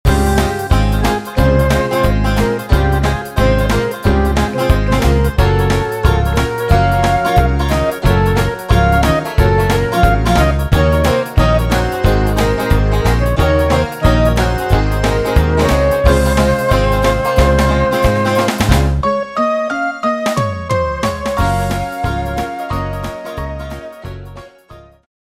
fichier midi
karaoké